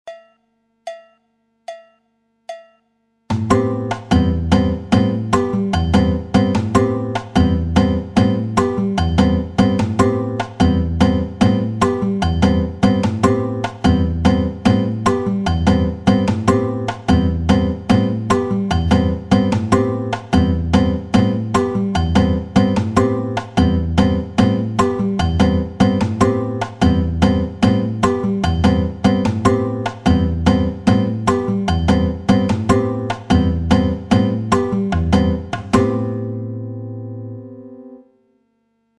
Variation de la figure 1 du partido alto à la guitare.
une variante utilisée dans les tempos rapides avec un jeu de main droite adapté, dans la deuxième mesure et dans la quatrième mesure c'est l'annulaire et le majeur qui pince les cordes sur l'accent, l'index jouant lui la note suivante en l'occurence la note do 3ème corde ce qui donne un balancement, le groove. le partido alto 2 guitare seule avec la section rythmique partido alto 2.